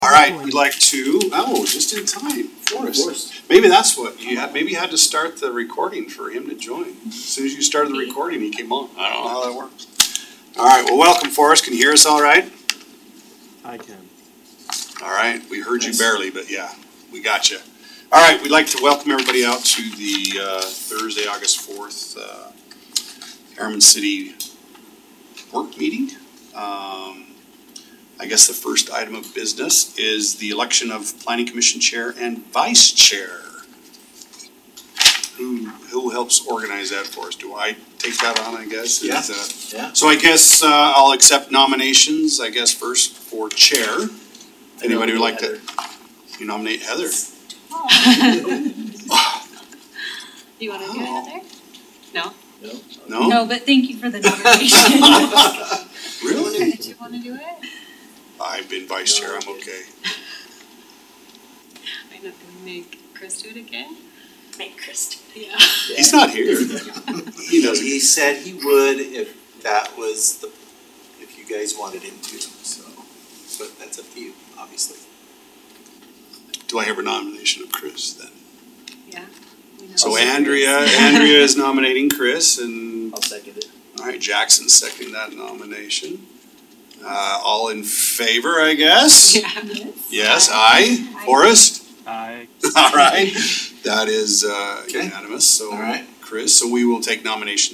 Meeting